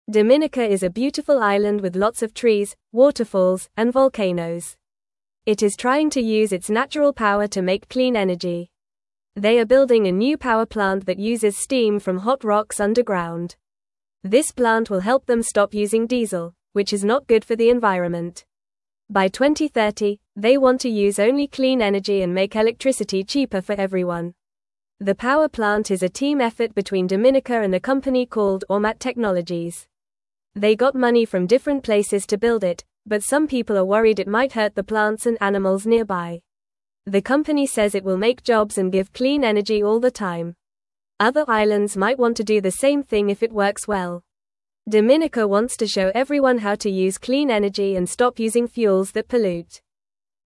Fast
English-Newsroom-Beginner-FAST-Reading-Dominicas-Clean-Energy-Plan-for-a-Bright-Future.mp3